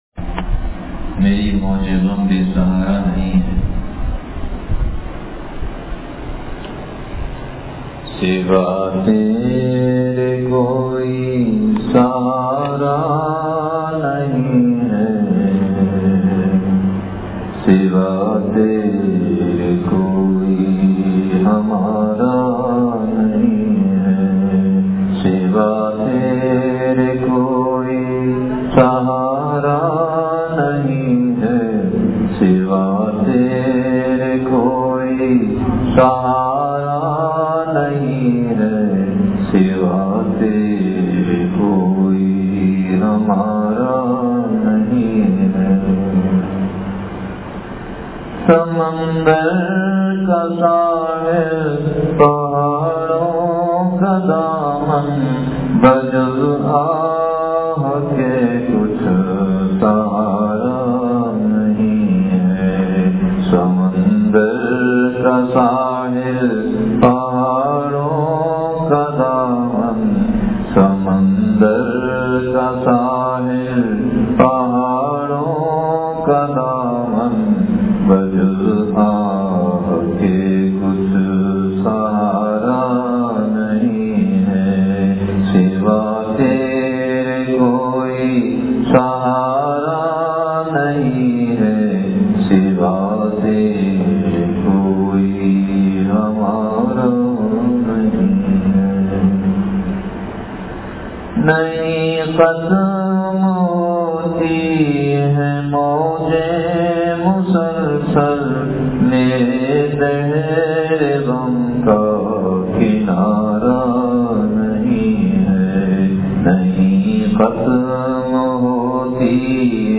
بیان بعد نمازِ عشاء مدنی مسجد پھلیلی حیدرآباد